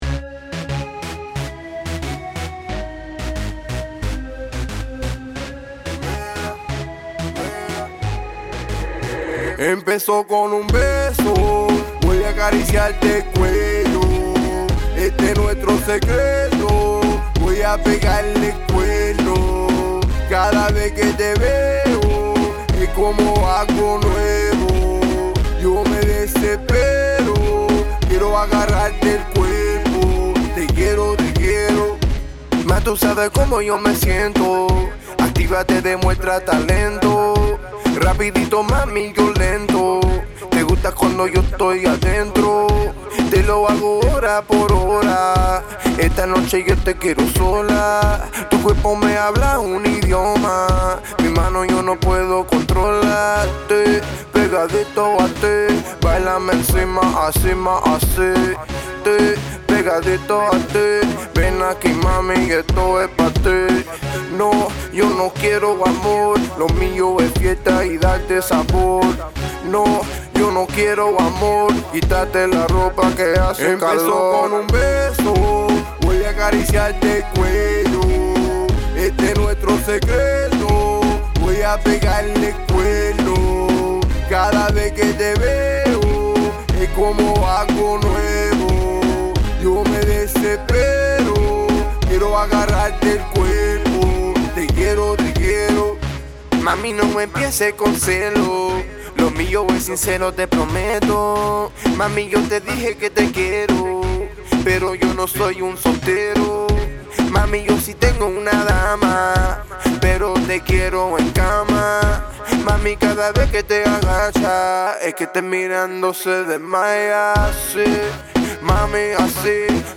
Reggeaton